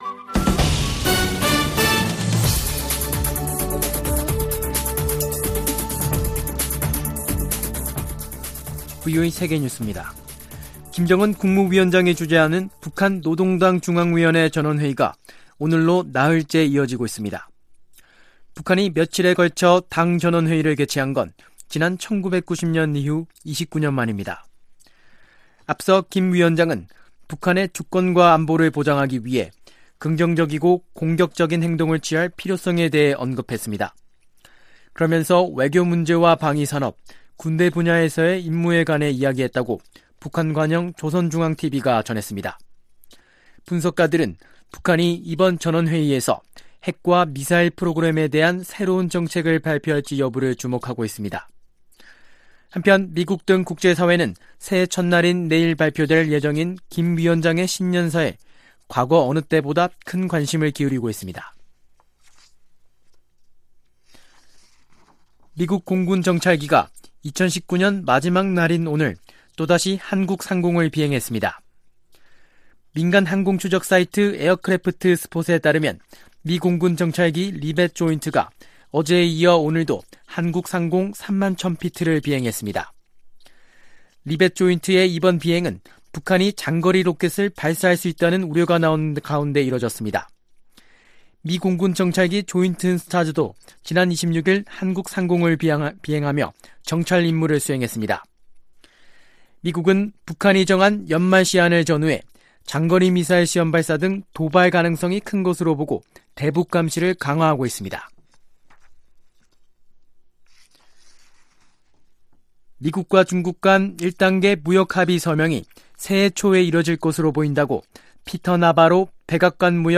VOA 한국어 간판 뉴스 프로그램 '뉴스 투데이', 2019년 12월 31일 3부 방송입니다. 북한의 노동당 전원회의가 길어지면서 그만큼 북한이 내년 한 해를 대단히 엄중하고 무겁게 바라보고 있다는 분석이 나옵니다. 마이크 폼페오 미 국무장관은 '연말 시한'과 관련해 북한을 예의 주시하고 있다며, 북한이 올바른 결정을 내리기를 바란다고 말했습니다.